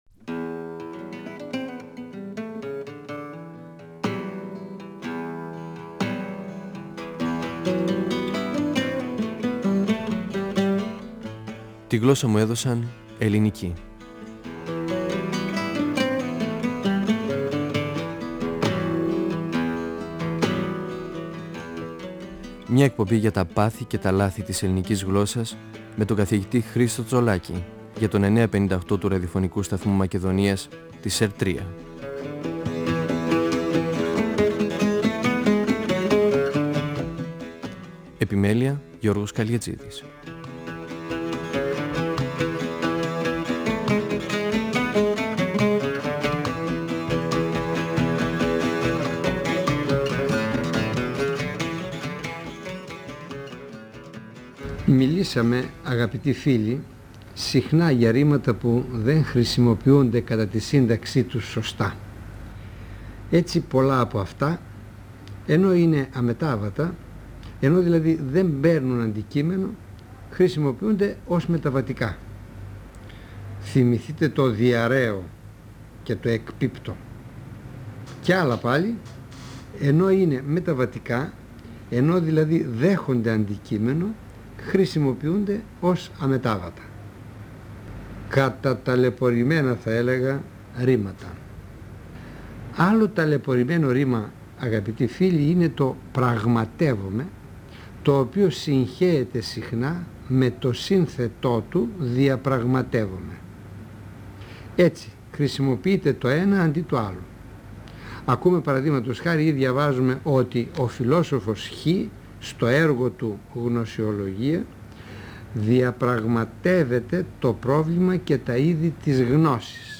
Ο γλωσσολόγος Χρίστος Τσολάκης (1935-2012) μιλά για τη σύγχυση στη χρήση των ρημάτων «πραγματεύομαι» και «διαπραγματεύομαι», «ασκώ» και «εξασκώ». Παρουσιάζει την ορθή χρήση των ρημάτων: «περιηγούμαι», «αμφιβάλλω», «συντρέχω», «διαφέρω».